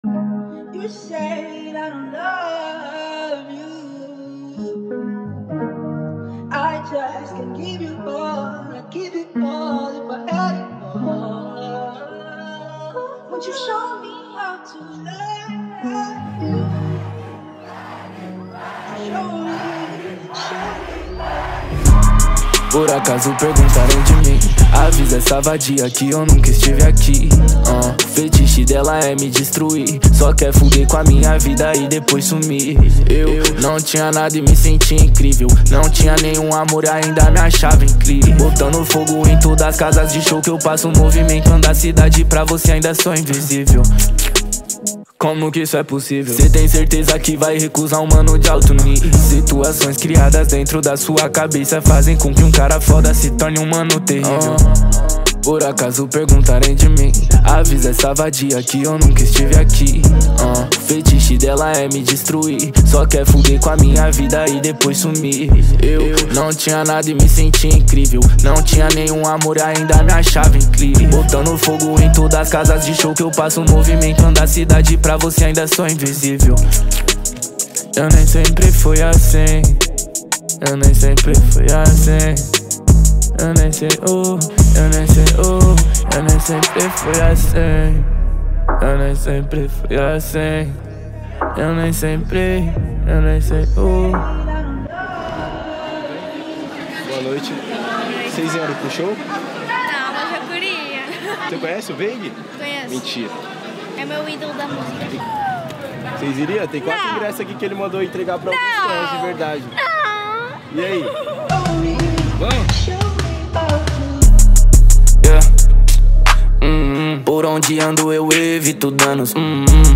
2025-02-10 19:14:26 Gênero: Rap Views